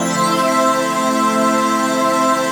ATMOPAD12.wav